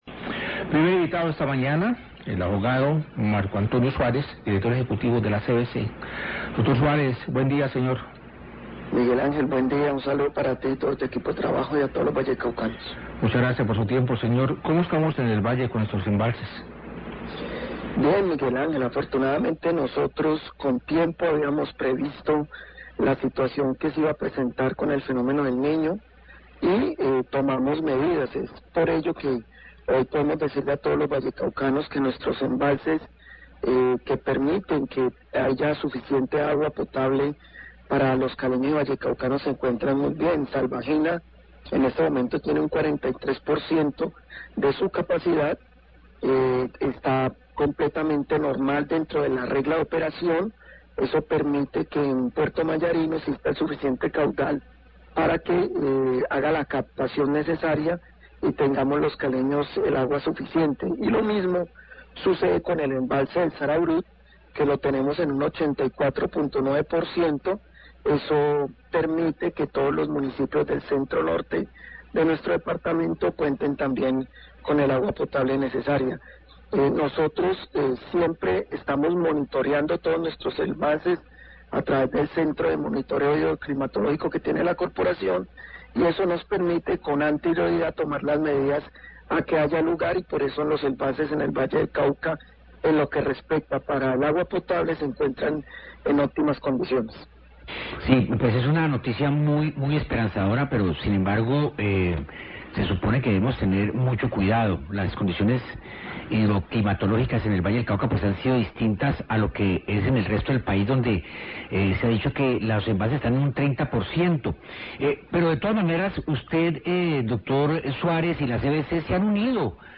Radio
entrevista